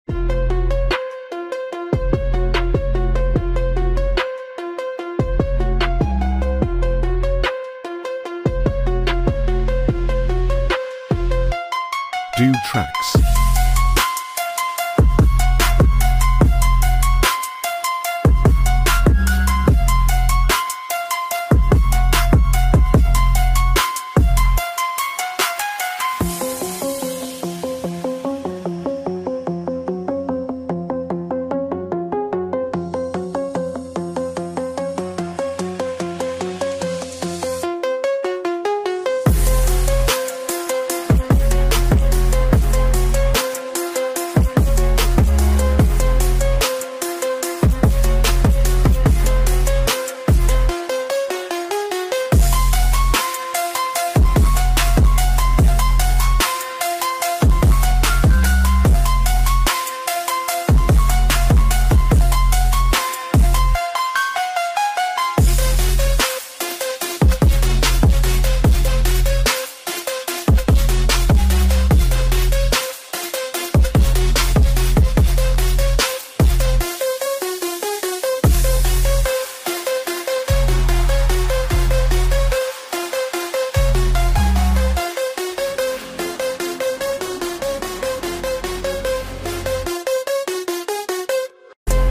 Royalty-Free Hip Hop Beat